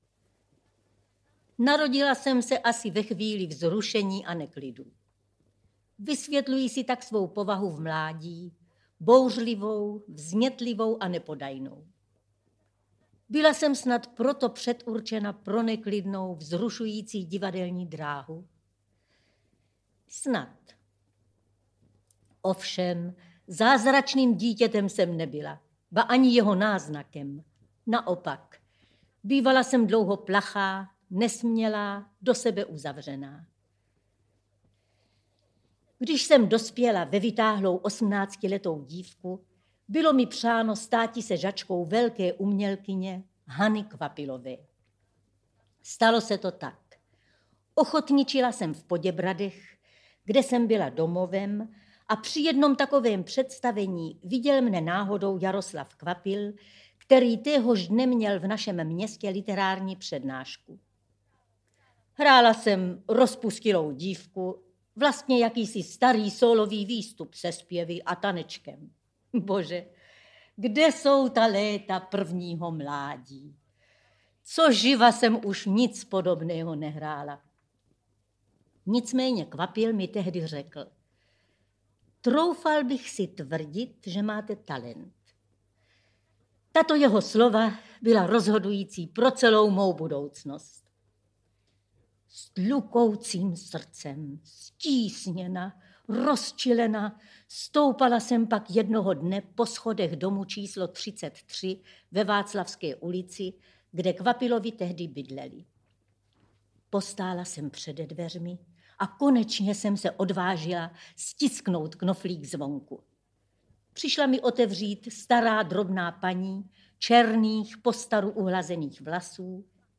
Vzpomínky národní umělkyně Leopoldy Dostalové, národní umělkyně Otýlie Beníškové - Leopolda Dostalová - Audiokniha
• Čte: Leopolda Dostalová